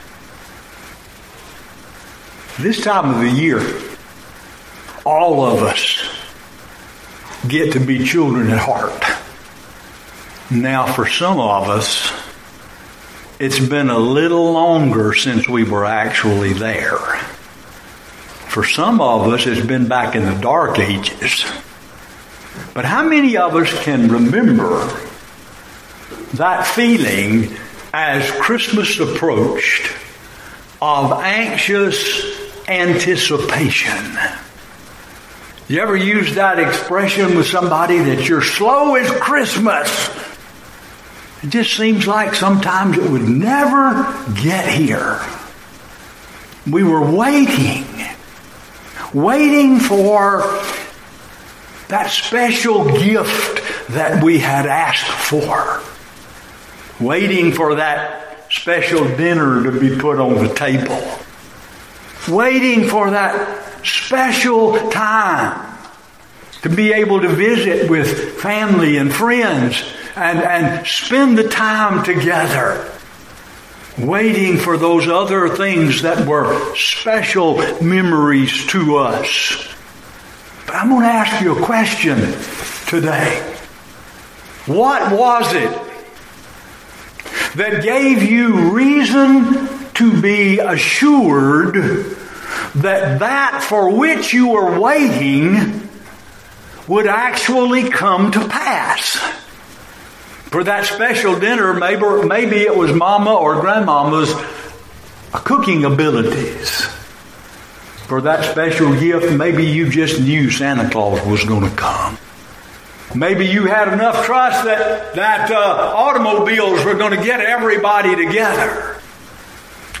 Sermons Previously Used